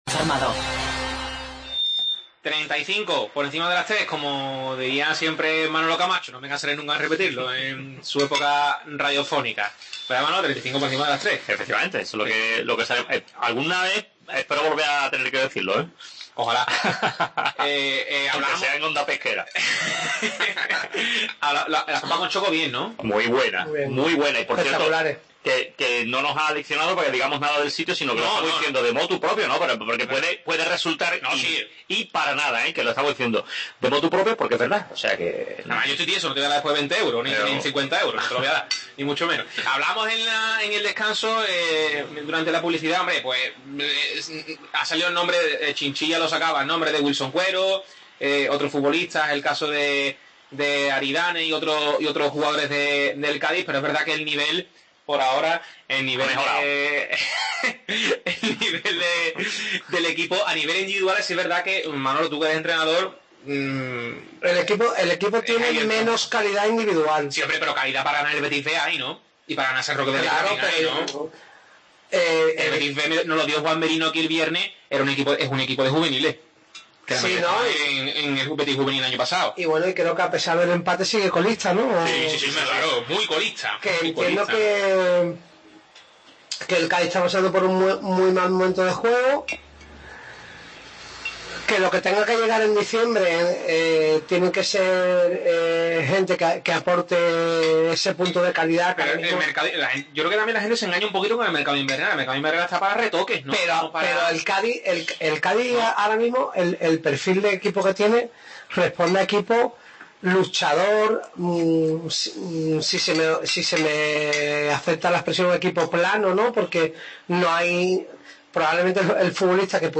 AUDIO: Segunda parte de la tertulia desde el Restaurante De Otero analizando la actualidad del Cádiz